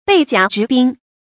被甲执兵 bèi jiǎ zhí bīng 成语解释 甲：古代打仗时军人穿的护身衣服。